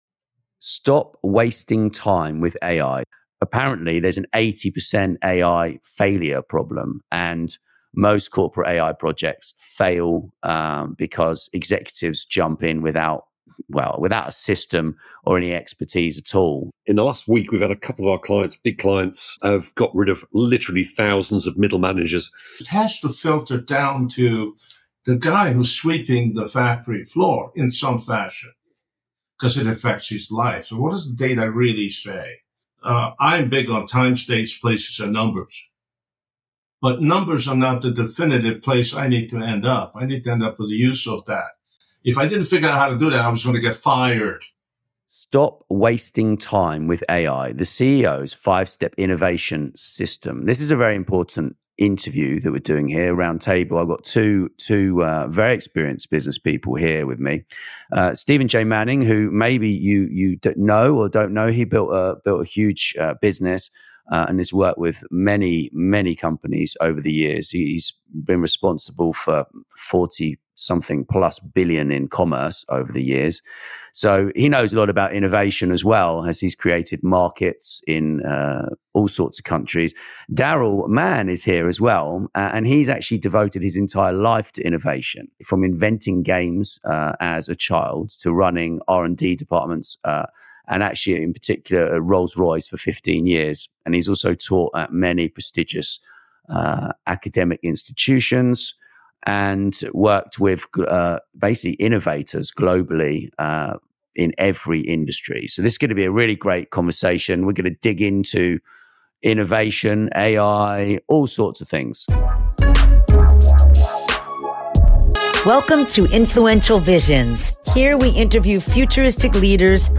Together, they expose why 80% of corporate AI projects fall flat and share a powerful, five-step system for business leaders and founders to move beyond the hype and start driving real innovation. This is a no-fluff conversation filled with actionable insights for non-technical executives.